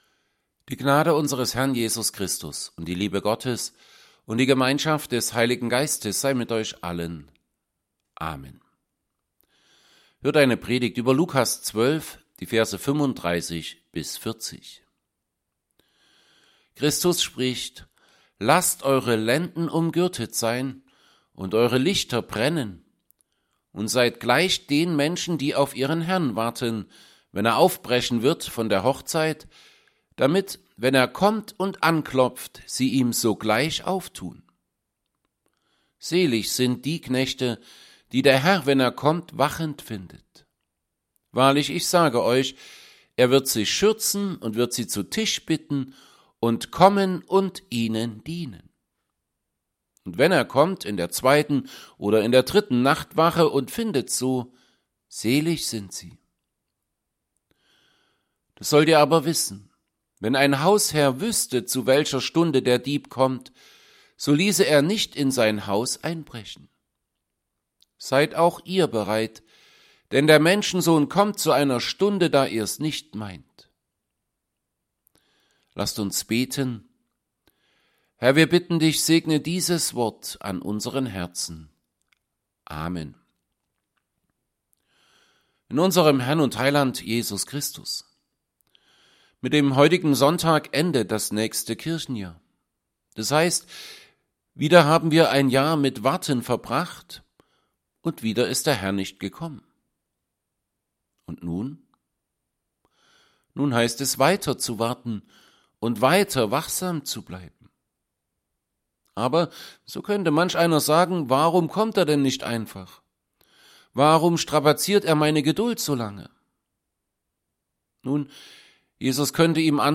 Evangelienpredigten Passage: Luke 12:35-40